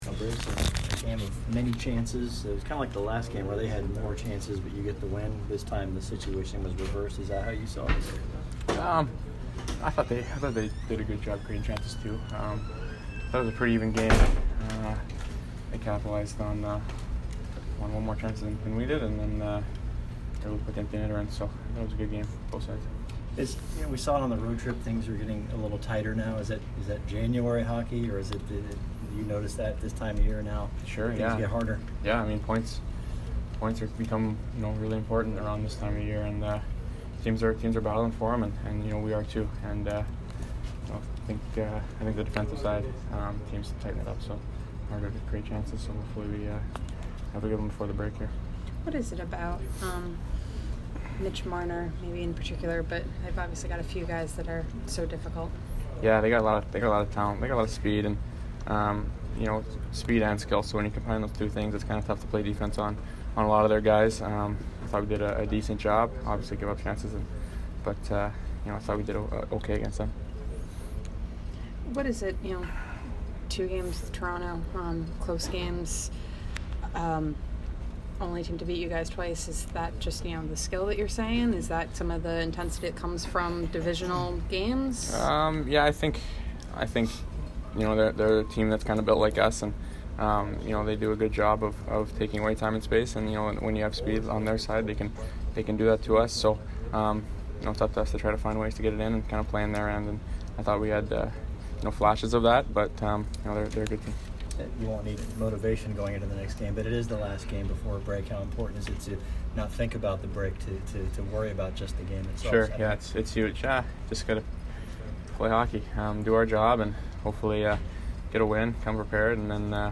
Brayden Point post-game 1/17